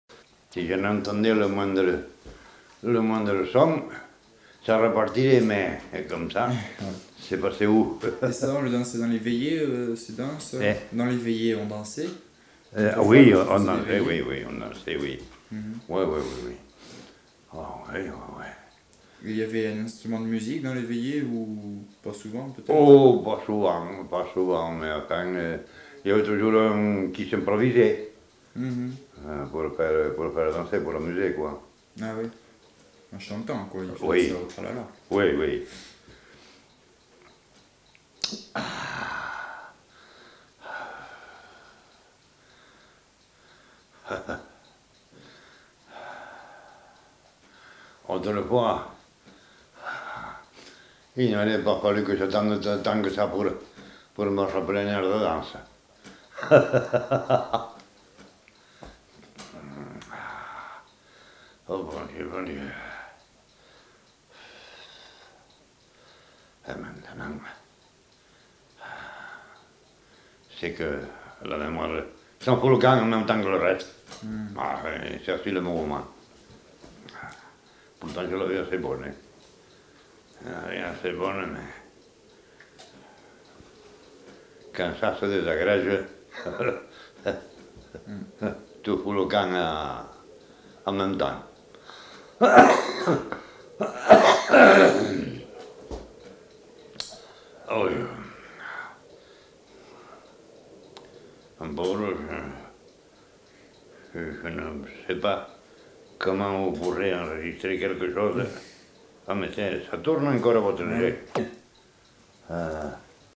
Aire culturelle : Couserans
Lieu : Uchentein
Genre : témoignage thématique